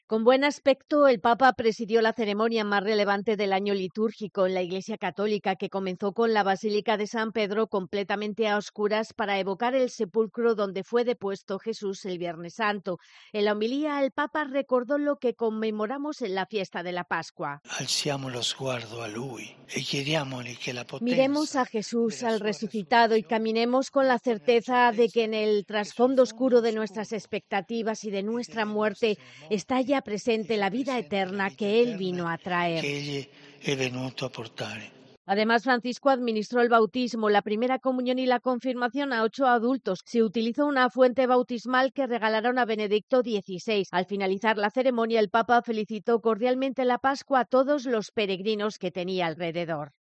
El Papa Francisco ha presidido este sábado la misa de la Vigilia Pascual en la Basílica de San Pedro, después de renunciar al Vía Crucis del Coliseo para cuidar su salud, y en la homilía pidió "que se aleje la desesperación" para "los pueblos destruidos por el mal y golpeados por la injusticia".
En esta larga celebración de más de dos horas, en la que se conmemora la Resurrección de Jesús, el Papa ha participado en todos los ritos y ha leído con buena voz, después de que este viernes decidió a última hora no acudir al Coliseo para el Vía Crucis.